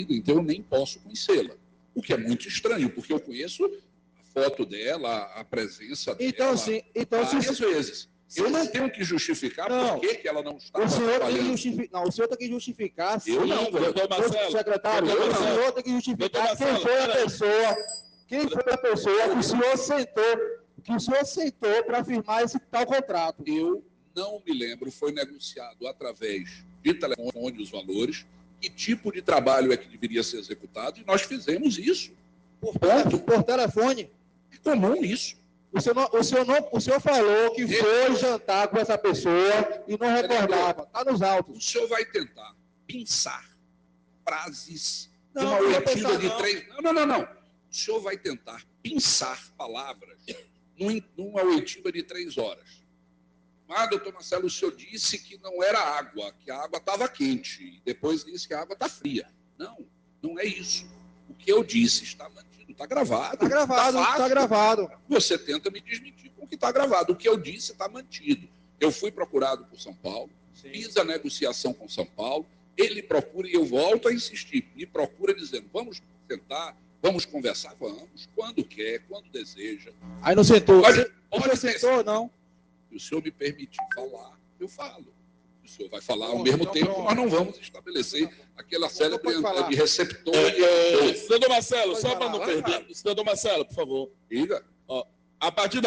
O secretário foi desmoralizado no plenário da Câmara ao mentir no depoimento da CPI nesta terça-feira (12).
Ouçam os áudios no momento em que Dr. Marcelo teria mentido na CPI e foi duramente advertido pelos edis.